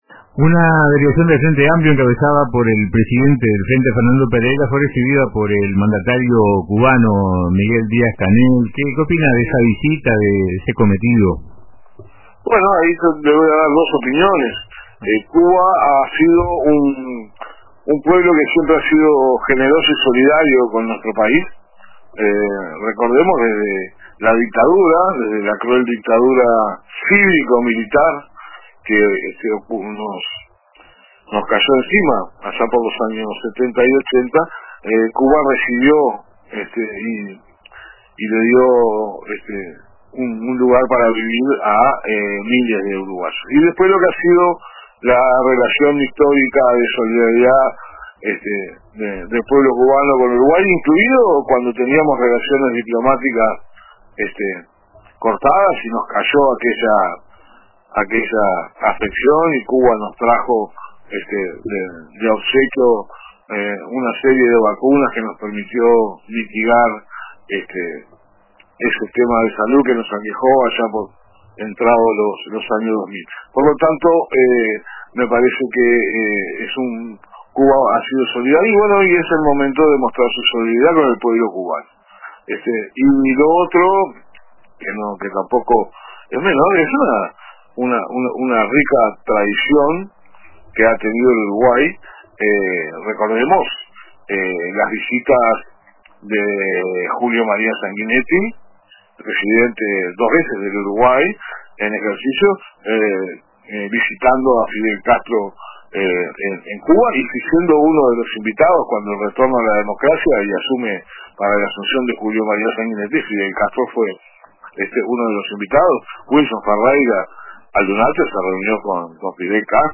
En ese marco, el senador por Maldonado, Eduardo Antonini, se refirió a la visita en declaraciones al programa Radio con Todos de RBC, donde destacó la histórica relación entre Uruguay y Cuba.